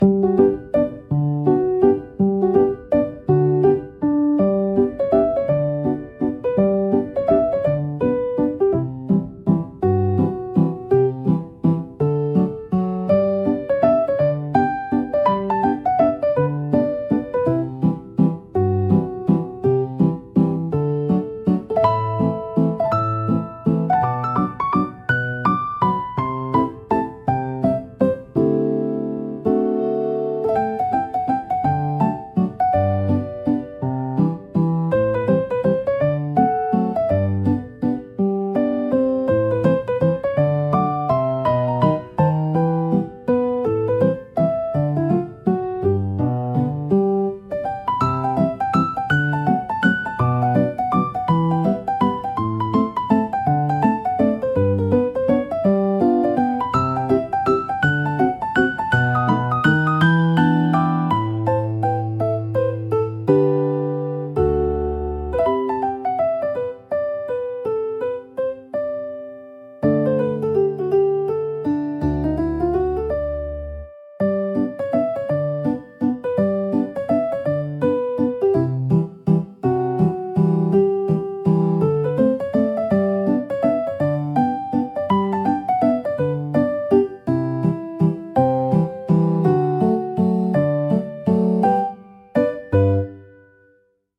ピアノの柔らかなタッチが集中をサポートし、疲れを癒す効果を発揮します。